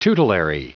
Prononciation du mot tutelary en anglais (fichier audio)
Prononciation du mot : tutelary